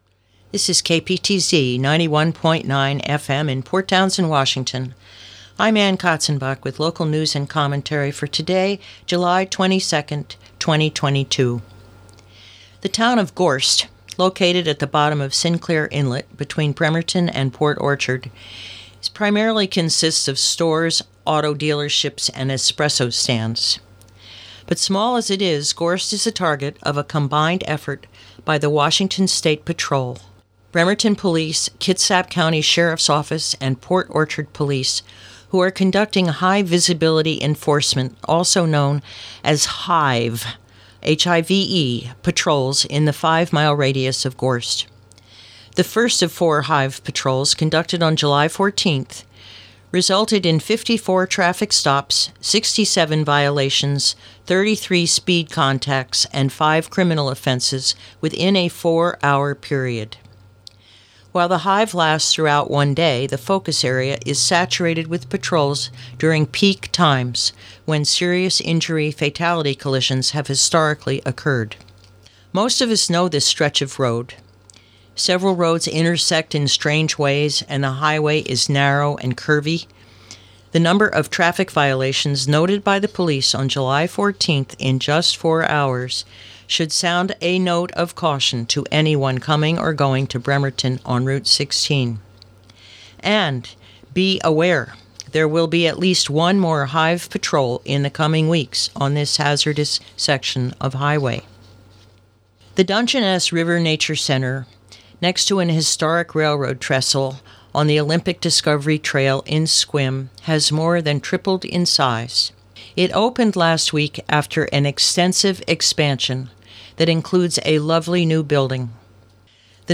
220722 Local News Fri